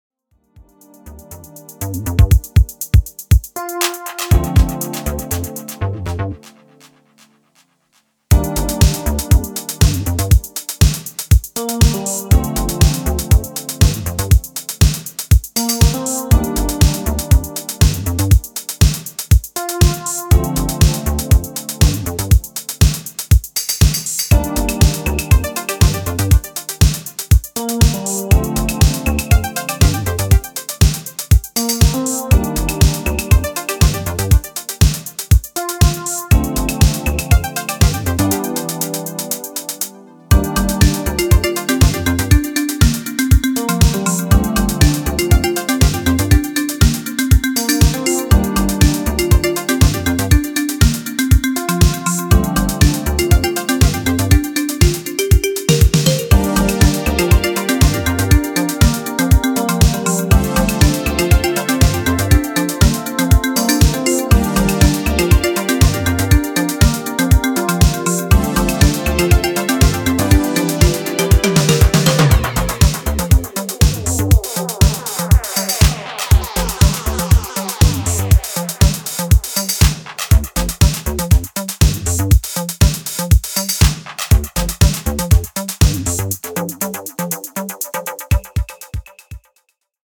proto-house, boogie and zouk